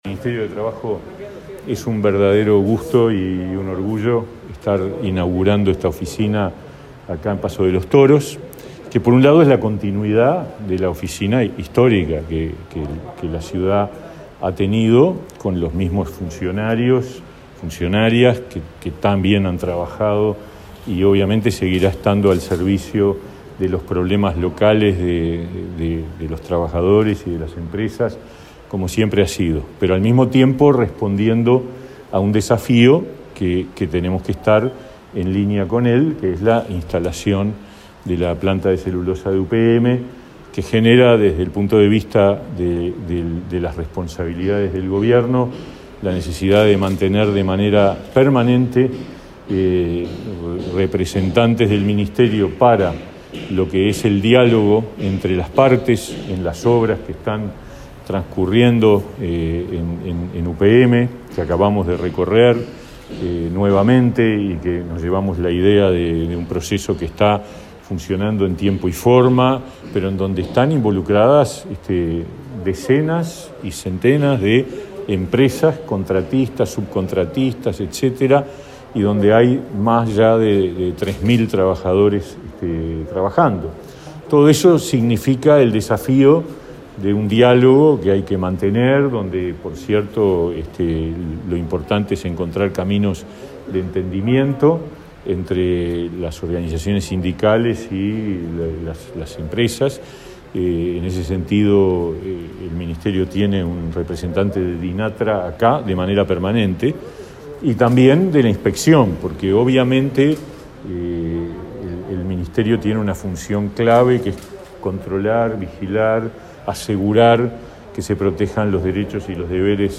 Declaraciones de Pablo Mieres en la inauguración de la nueva Oficina de Trabajo en Paso de los Toros